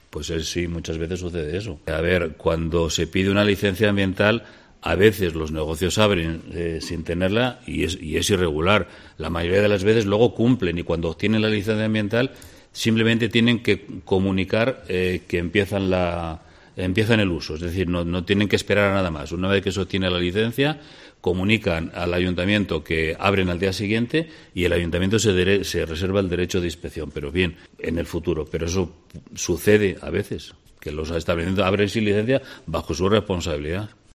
El alcalde de Zamora reconoce que varios establecimientos en Zamora abren sin licencia